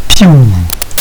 shoot2.wav